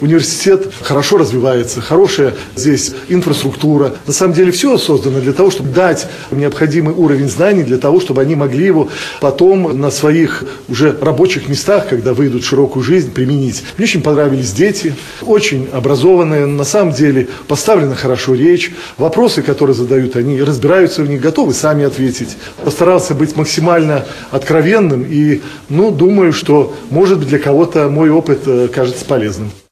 На базе Барановичского государственного университета состоялась диалоговая площадка со студентами с разных факультетов учреждения образования.